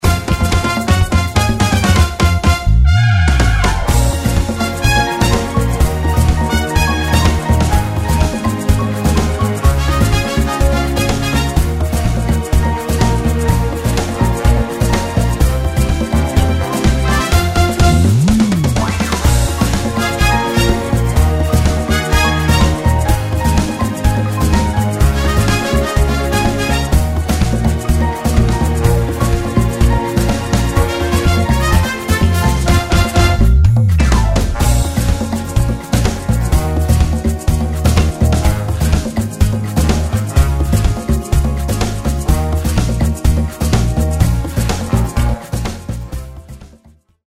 Pop , Musical , Film
Instrumental
backing track